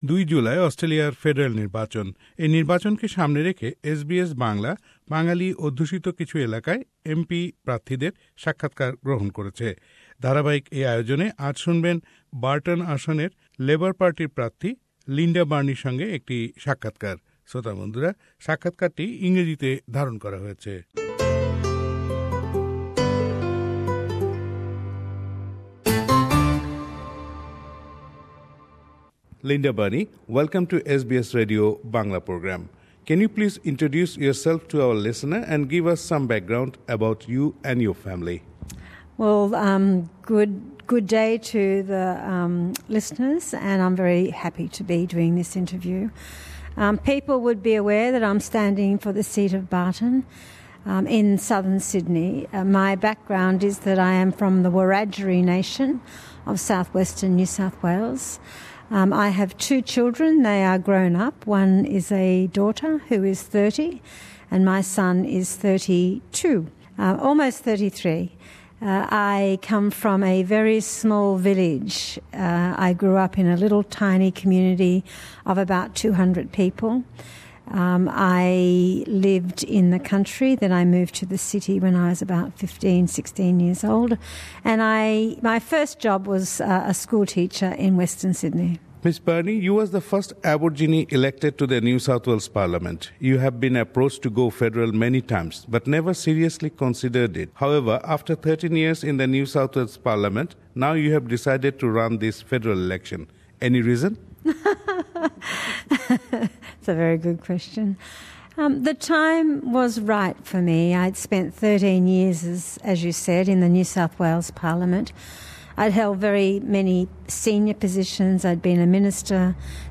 Interview with Linda Burney